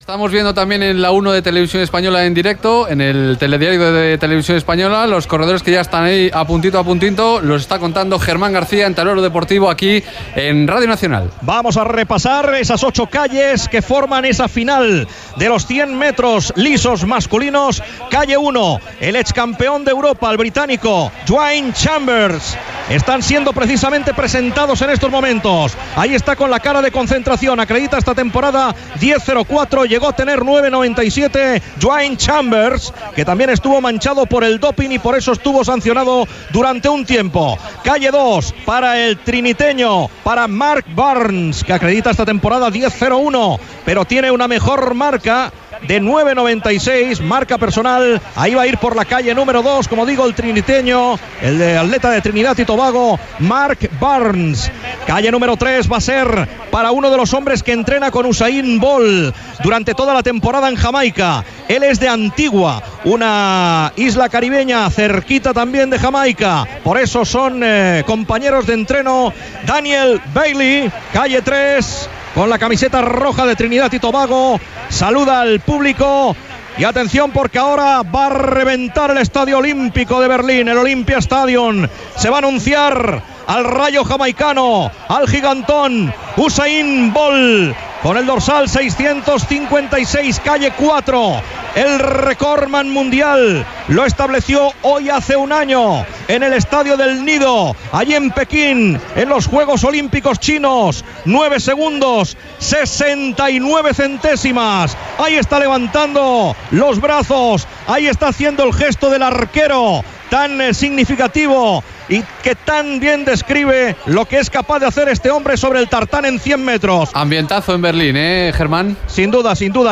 Transmissió, des de l'Estadi Olímpic de Berlín, de la cursa masculina dels 100 metres llisos en la qual Usain Bolt bat el temps del rècord del món de la prova que tenia des de feia un any
Esportiu